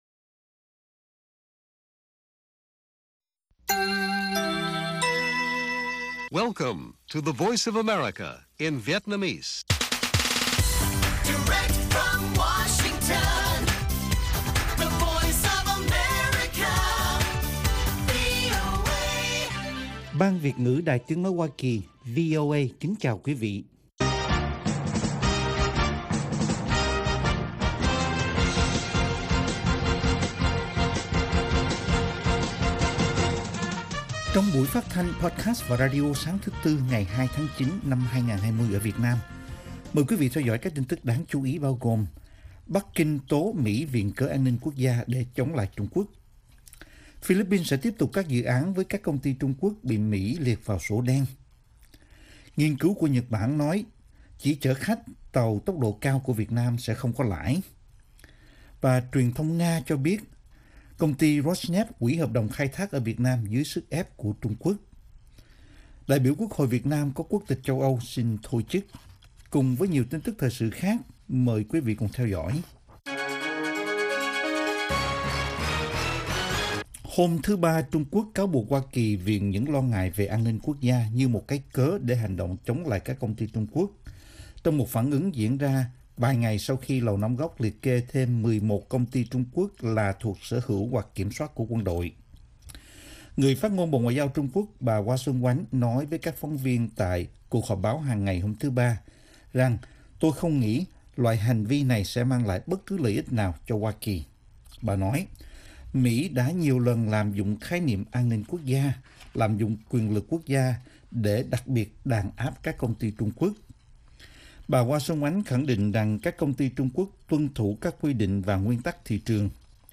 Bản tin VOA ngày 2/9/2020